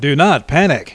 In 1995, Wired magazine's AOL forum located Elwood Edwards -- whose voice recorded the sound file "You've Got Mail" -- and had him record ten additional sound files.